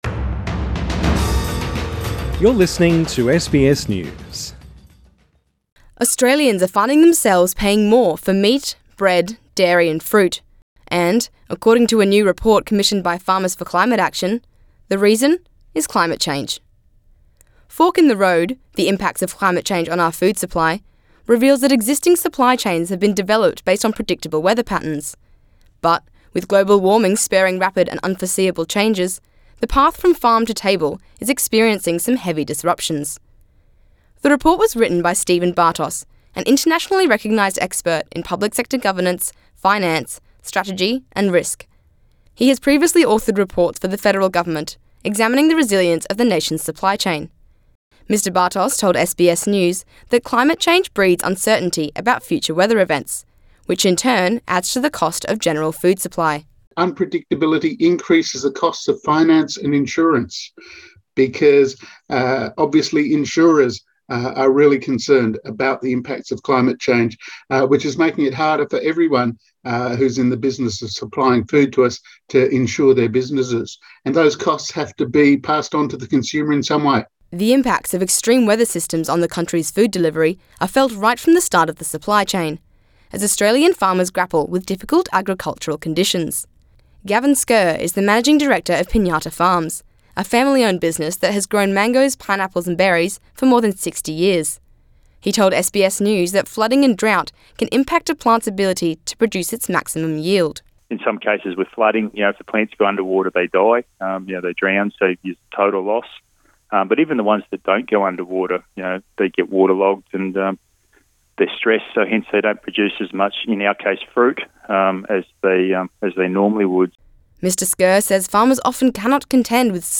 Radio news feature service: Farmers say a more pro-active approach to climate change is needed Share